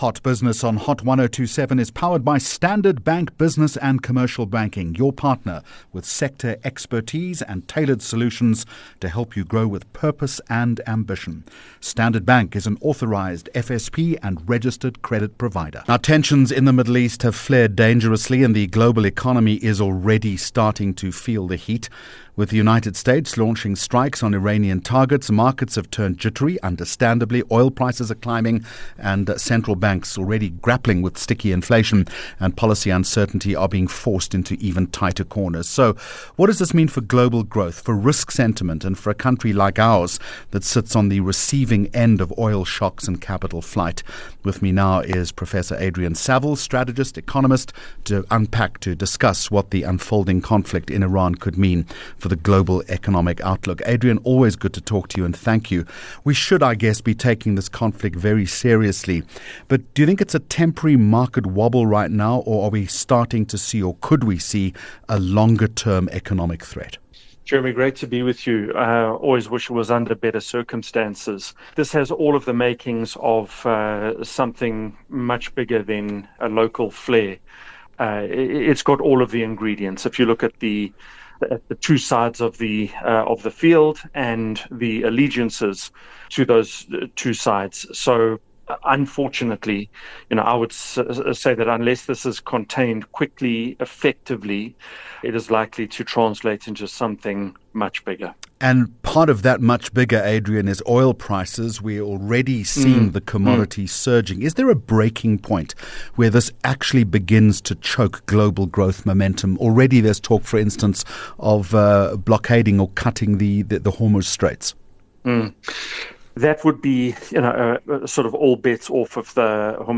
23 Jun Hot Business Interview